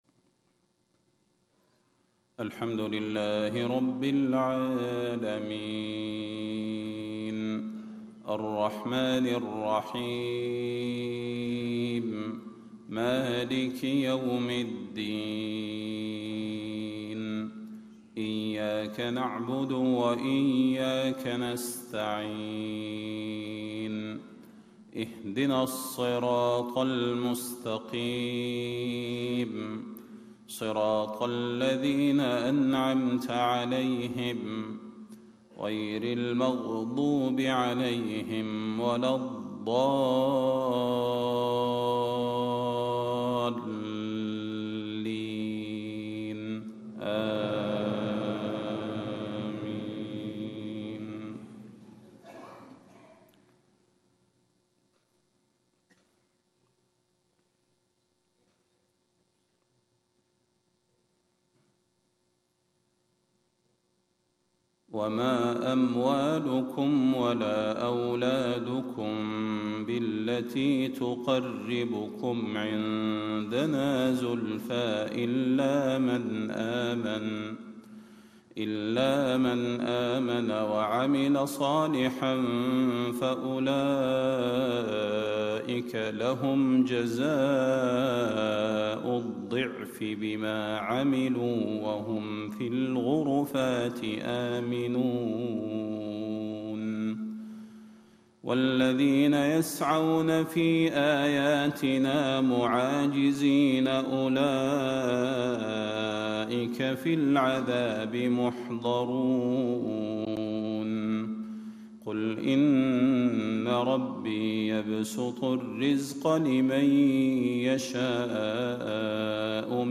صلاة الفجر 22 ربيع الاخر 1437هـ من سورة سبأ 37-50 > 1437 🕌 > الفروض - تلاوات الحرمين